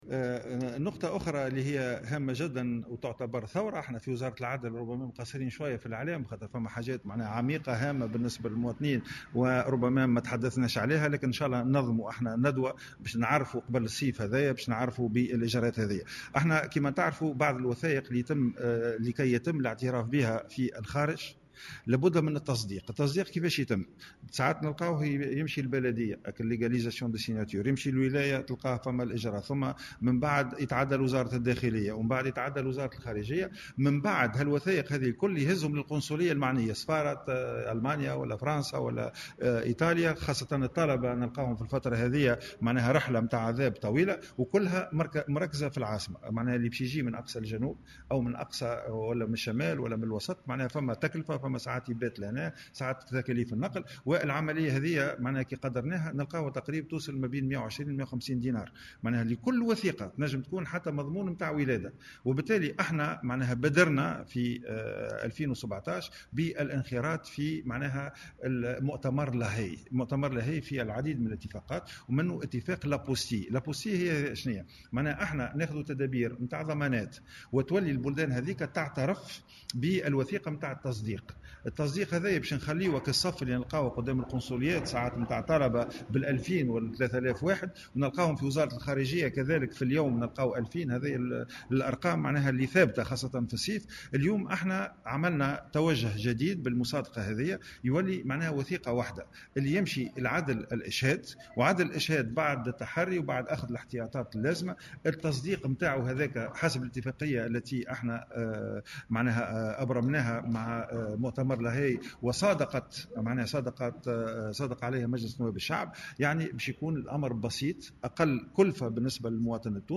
أعلن وزير العدل غازي الجريبي في تصريح لمراسل الجوهرة "اف ام" اليوم الاثنين اقرار جملة من الإجراءات لفائدة التونسيين بالخارج ولفائدة الطلبة التونسيين الراغبين في الدراسة في الخارج.